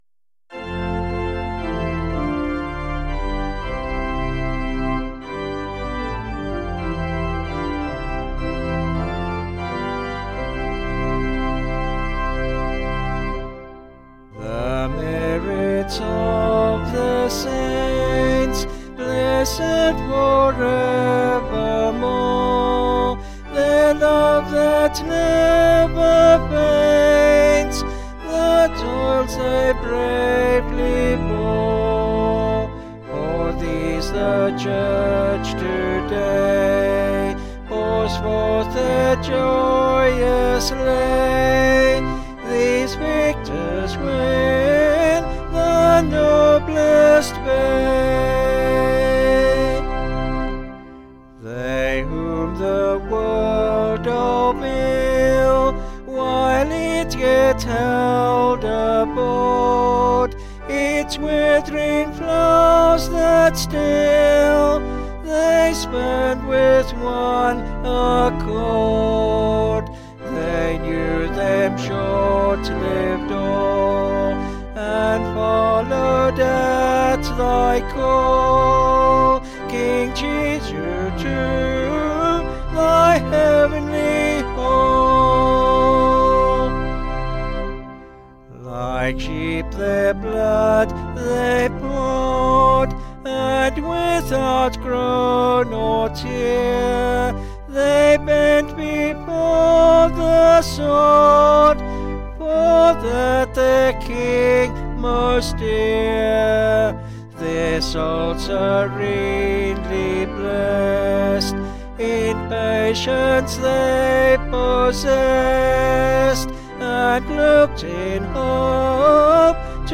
Vocals and Organ   705.1kb Sung Lyrics